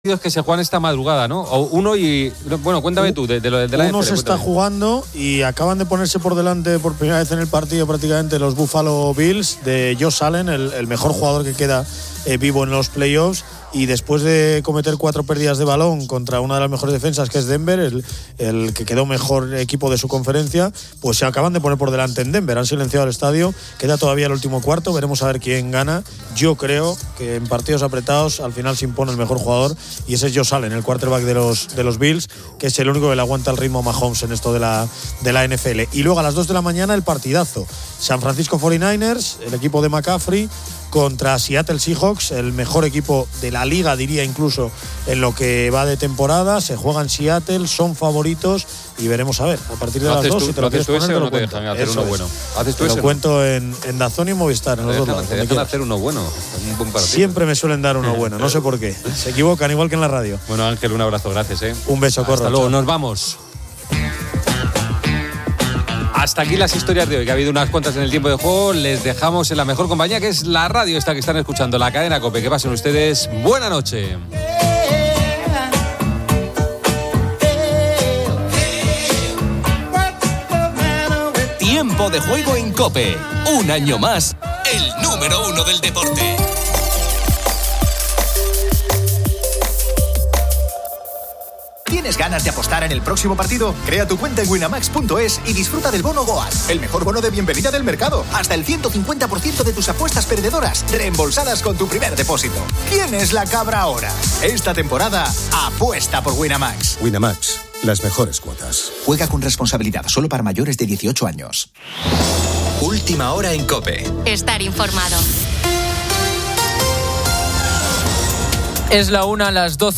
Una oyente relata un incidente al preguntarle a un hombre manco si estaba "mancado".